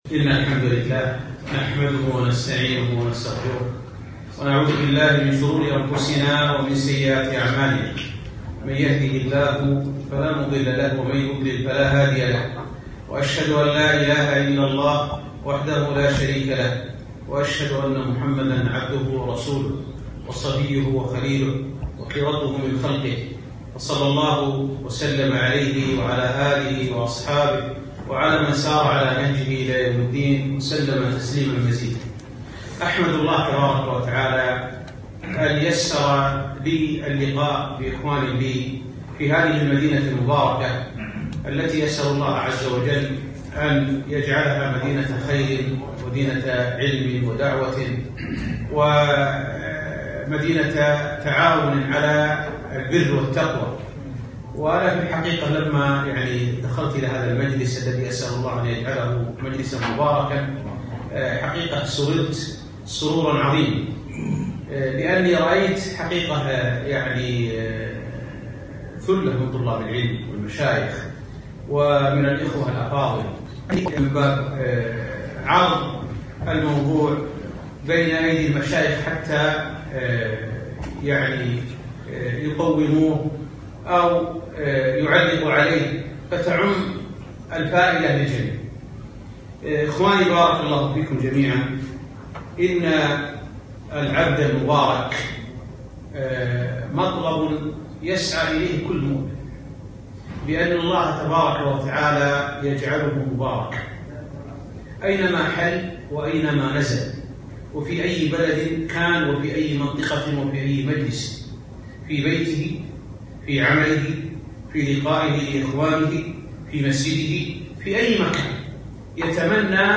محاضرة - كيف يكون العبد مباركاً